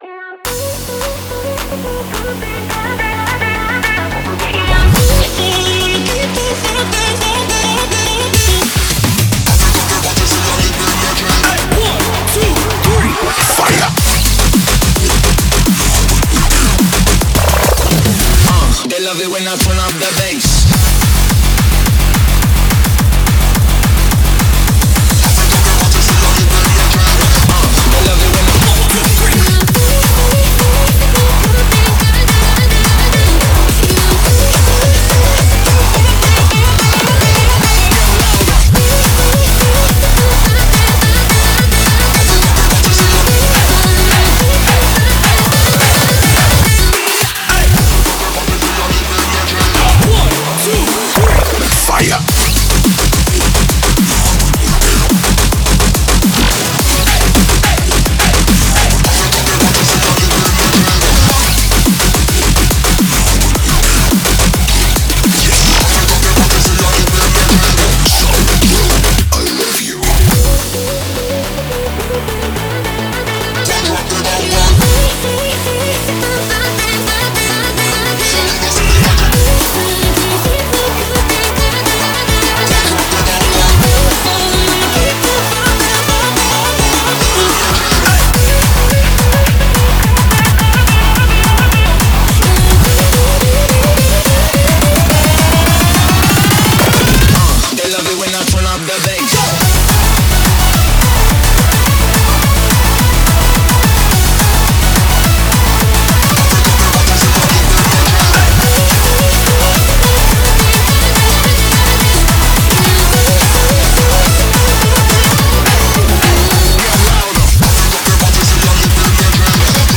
BPM107-213
Audio QualityPerfect (High Quality)